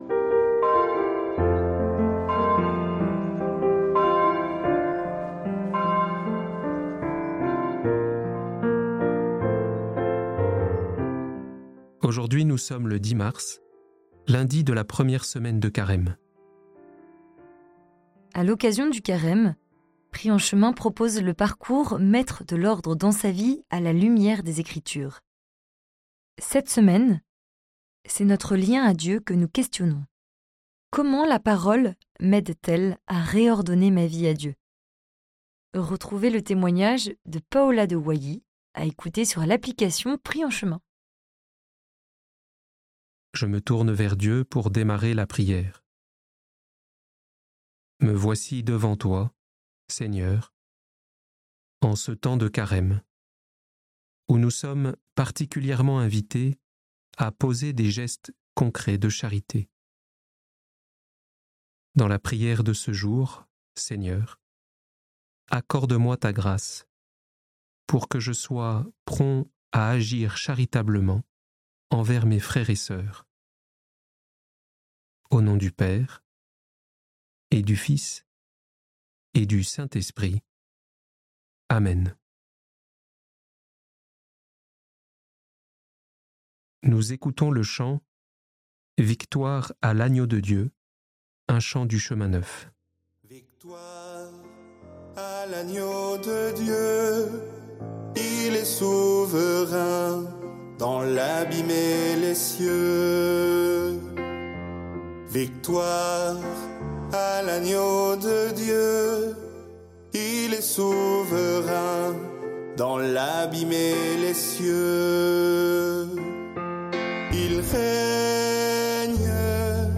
Chaque jour, retrouvez 12 minutes une méditation guidée pour prier avec un texte de la messe !